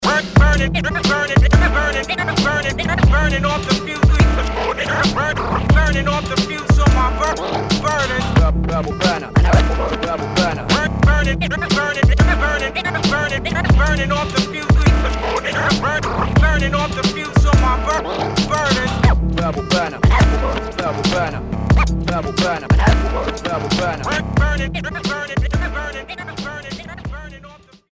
My then bandmates too - check the scratches on the track Berbal Verners.